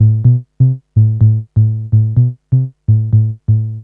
cch_bass_vegas_125_C.wav